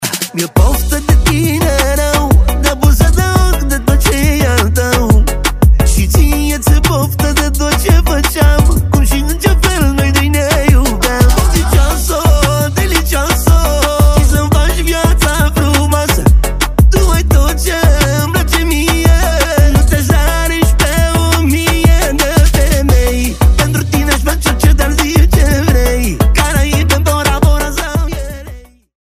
Categorie: Manele